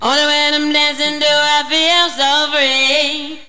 • Samples de  Voz